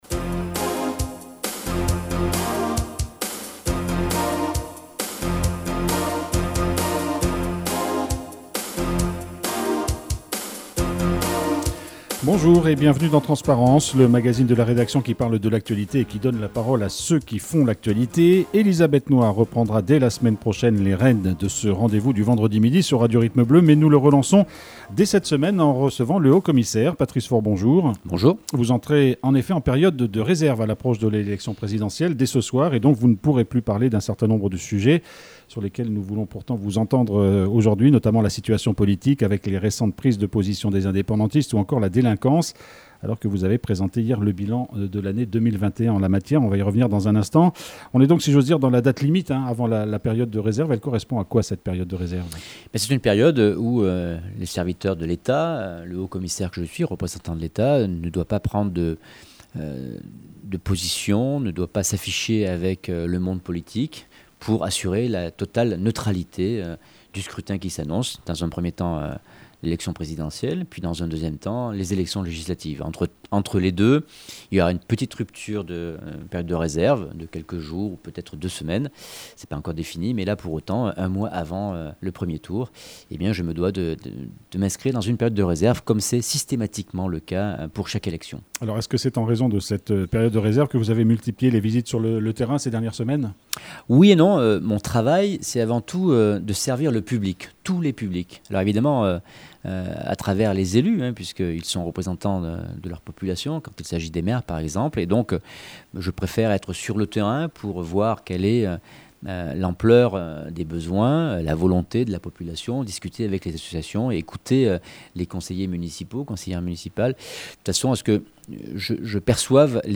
Menu La fréquence aux couleurs de la France En direct Accueil Podcasts TRANSPARENCE : 18/03/22 TRANSPARENCE : 18/03/22 17 mars 2022 à 15:06 Écouter Télécharger Patrice Faure, le Haut-Commissaire, était l'invité du magazine Transparence.